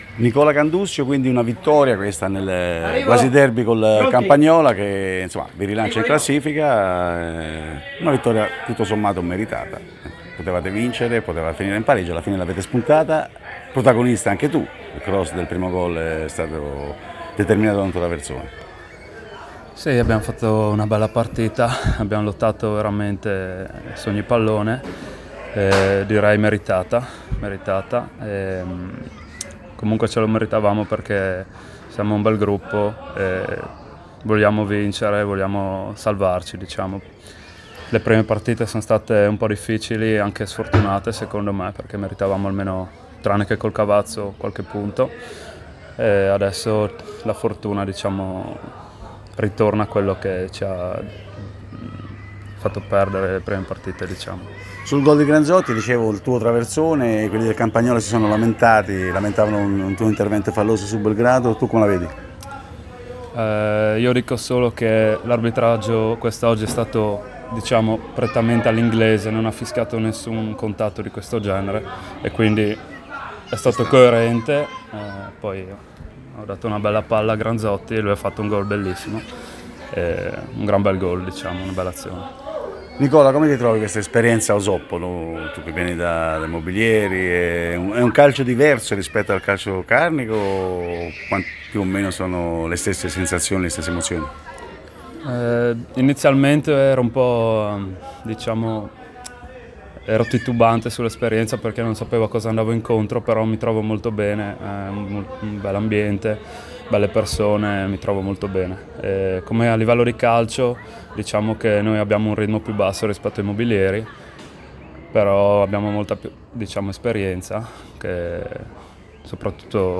Proponiamo le audiointerviste realizzate al termine di Nuova Osoppo-Campagnola, conclusa con la vittoria della squadra di casa per 2-1.